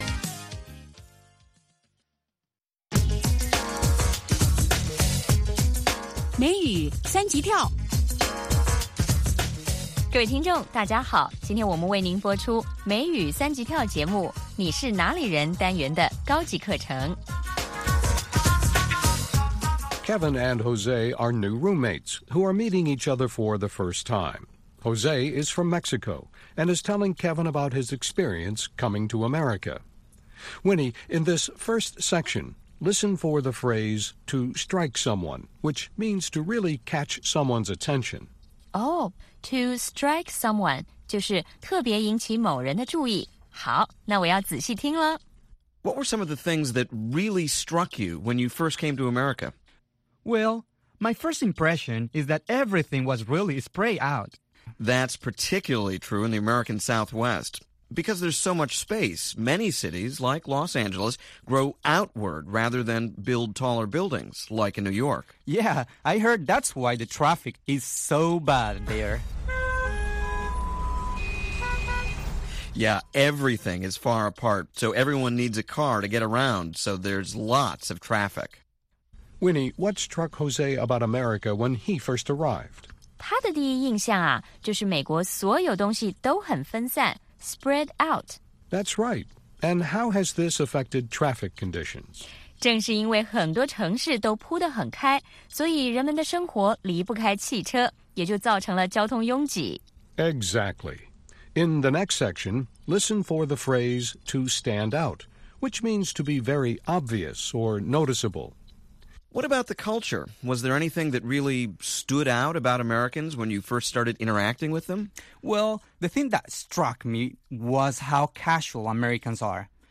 北京时间下午5-6点广播节目。广播内容包括收听英语以及《时事大家谈》(重播)